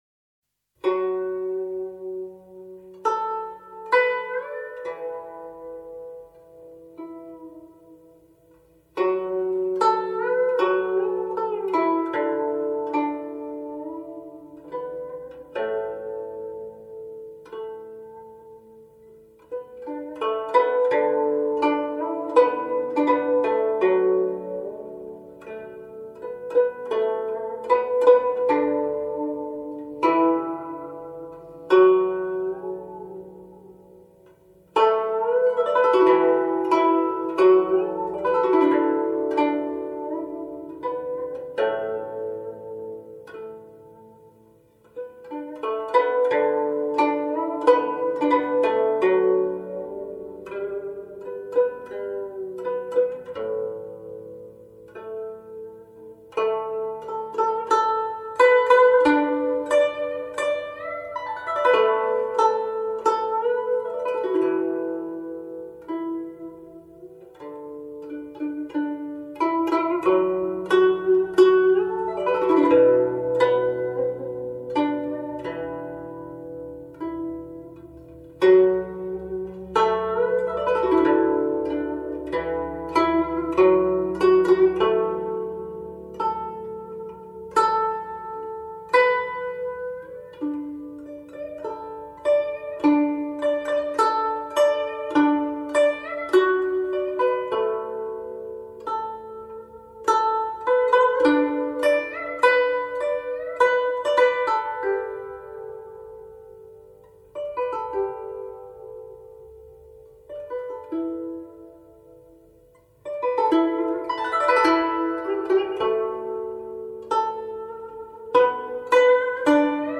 演奏乐器:古 筝
中 国 古典音乐
古筝——我国古代的弹拔乐器，其音色淳厚优美、轻柔、典雅、华丽而委婉，是一种极富表现力的乐器。
它那优美、动听的旋律描绘了江南渔船满载而归，歌声四起的情景。